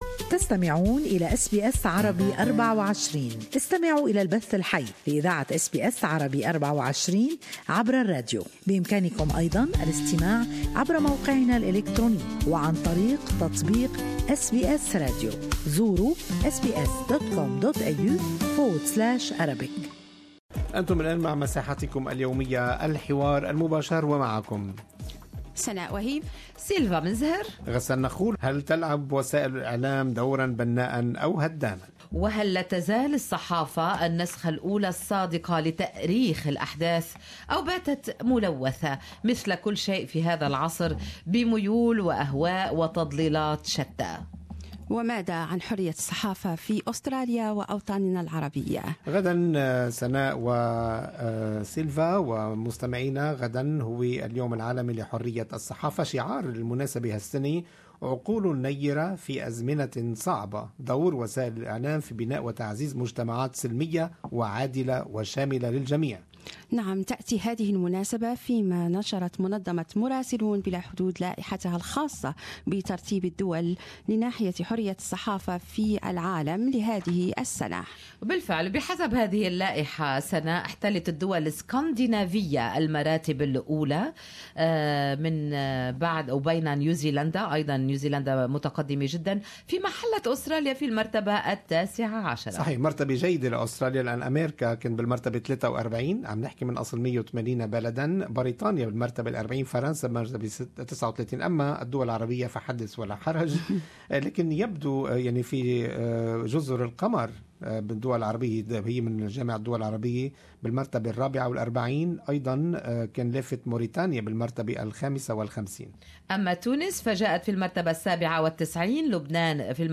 SBS Arabic 24 discussed the issue of "Freedom of Press" with its listeners;